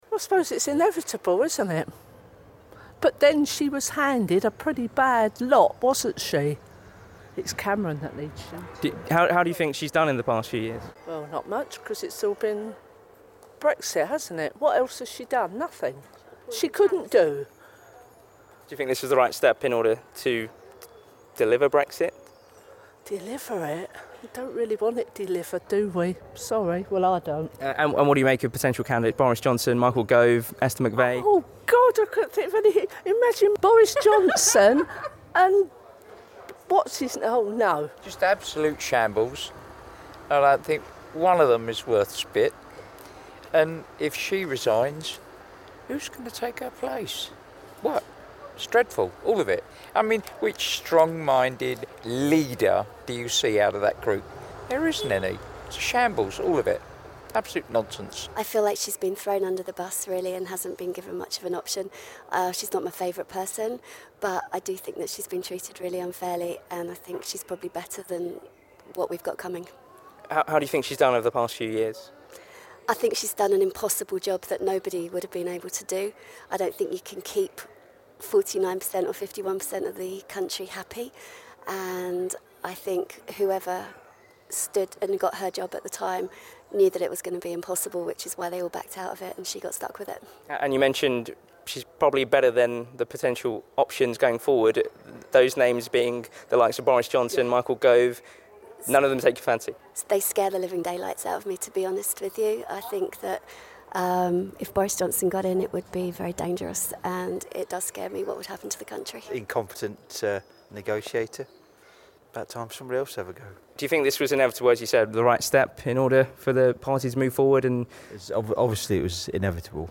LISTEN: People in Rochester react to the resignation of Theresa May - 24/05/2019